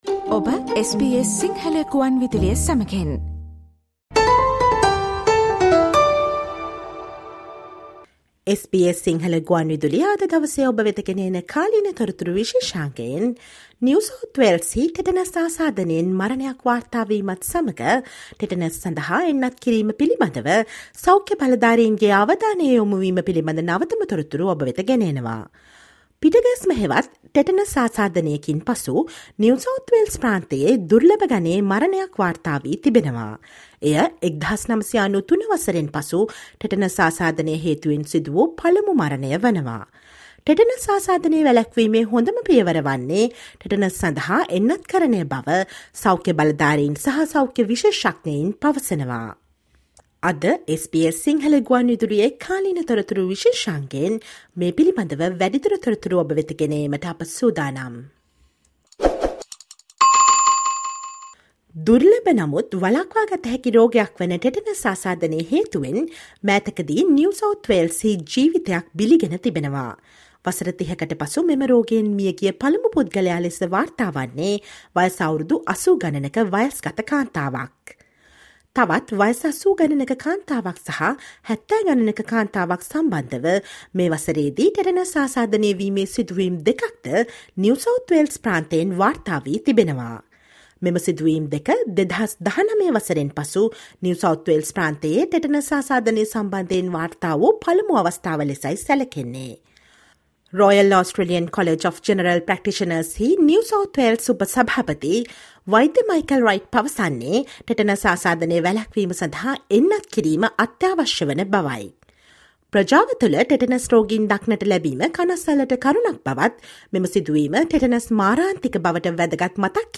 Listen to the SBS Sinhala radio current affair feature for the latest updates of health warnings on Tetanus by health experts across Australia.